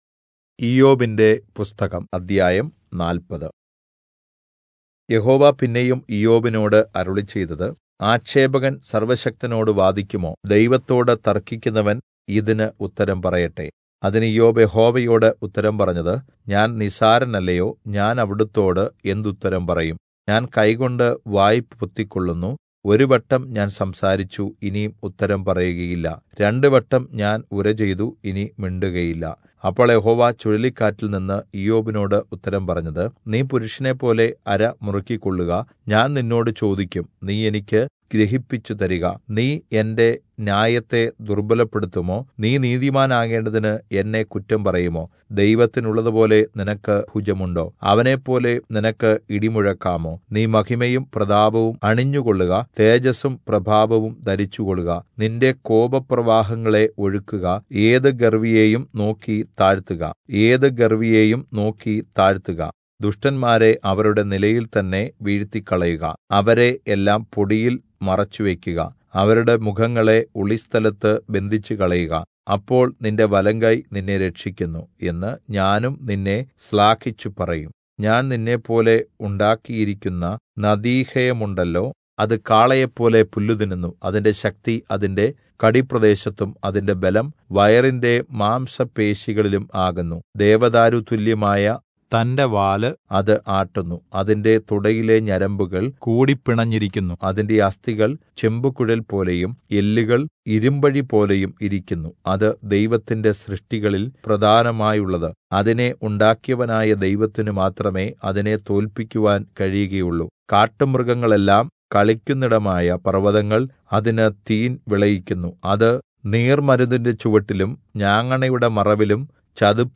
Malayalam Audio Bible - Job 42 in Irvml bible version